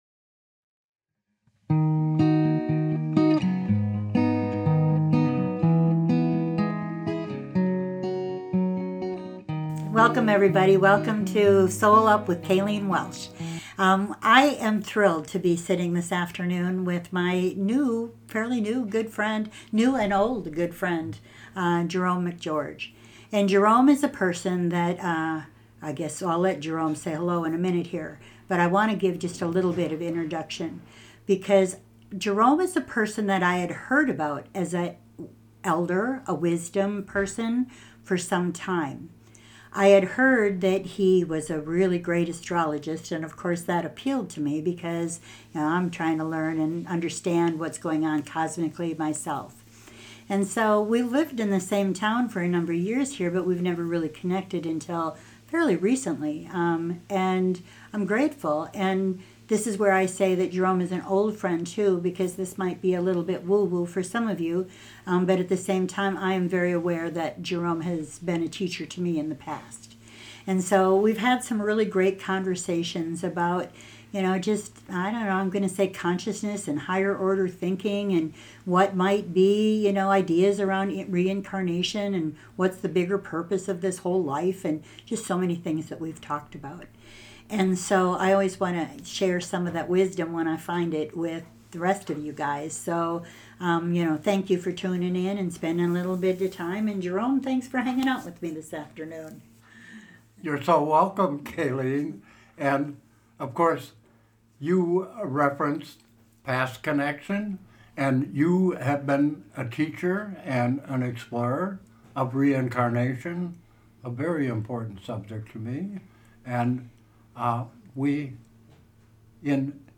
Settle in and get cozy for this rich conversation.